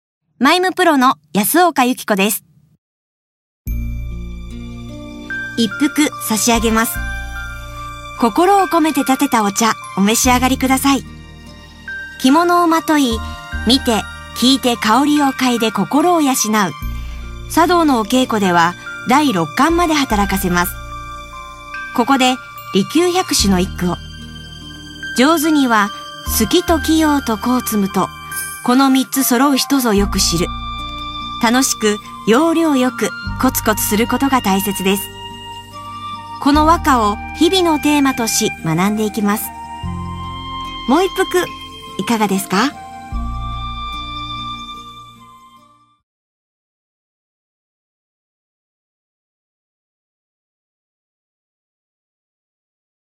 出⾝地・⽅⾔ 大阪府・関西弁
ボイスサンプル